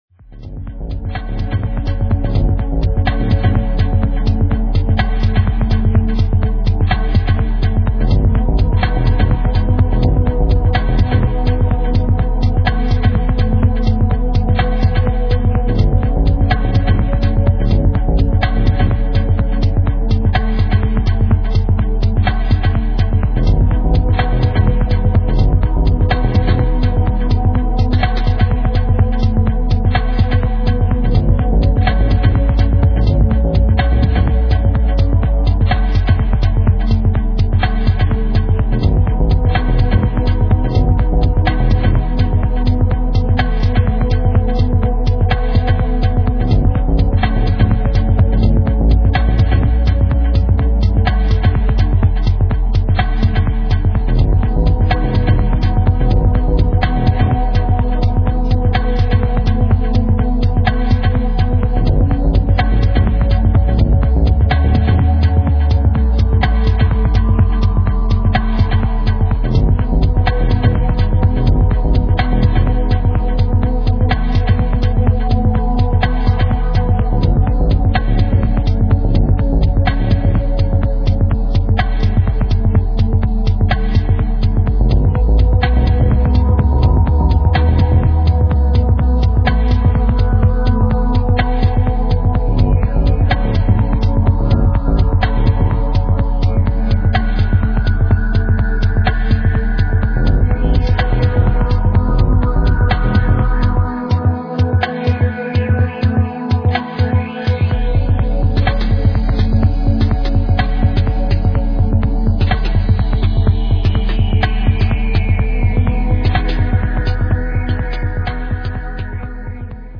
French duo